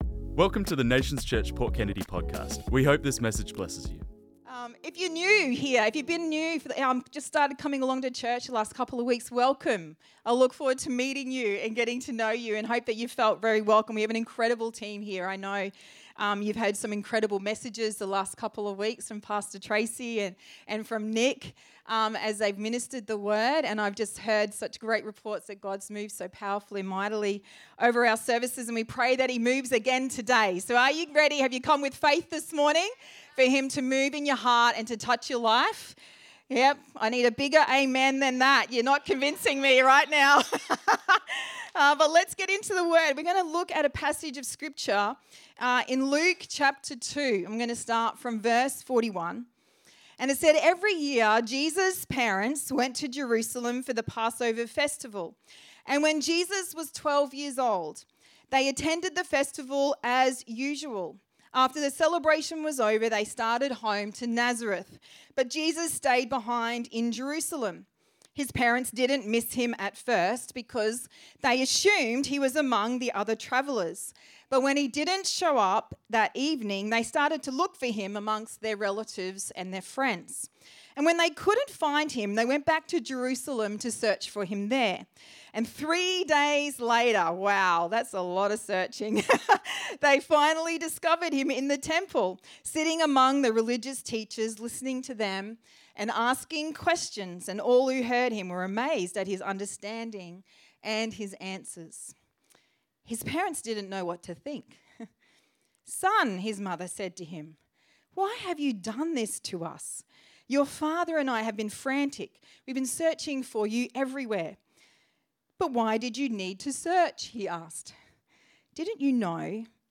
This message was preached on Sunday the 18th Jan 2026